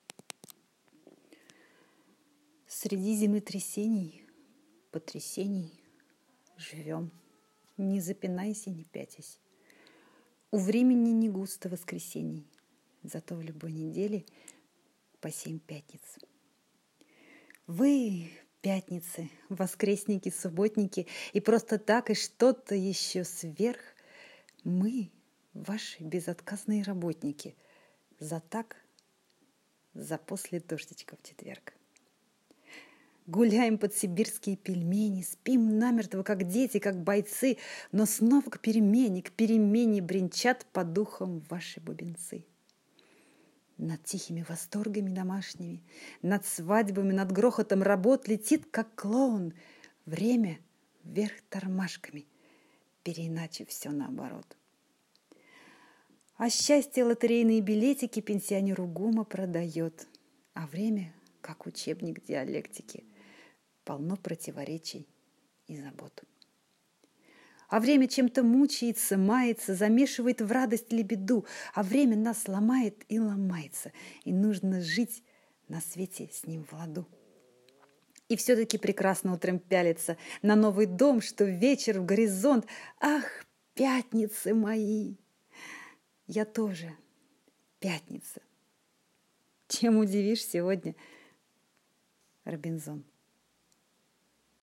(читает)